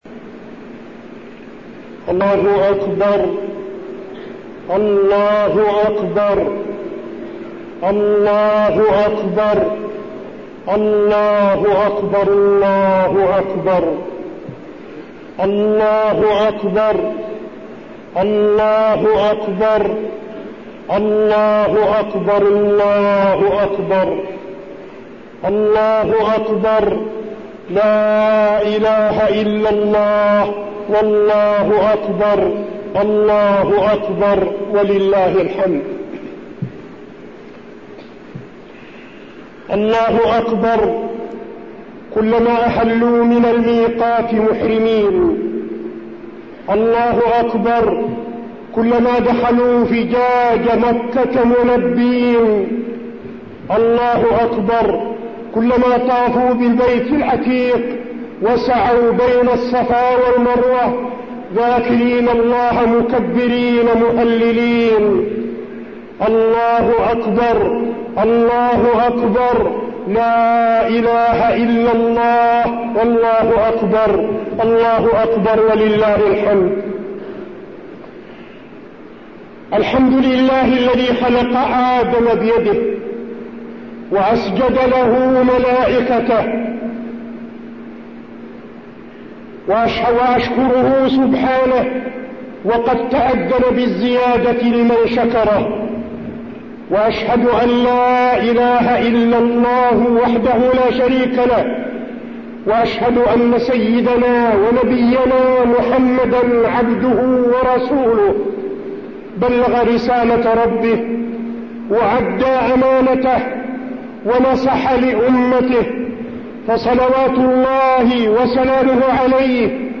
خطبة عيد الأضحى - المدينة - الشيخ عبدالعزيز بن صالح
تاريخ النشر ١٠ ذو الحجة ١٤٠٣ هـ المكان: المسجد النبوي الشيخ: فضيلة الشيخ عبدالعزيز بن صالح فضيلة الشيخ عبدالعزيز بن صالح خطبة عيد الأضحى - المدينة - الشيخ عبدالعزيز بن صالح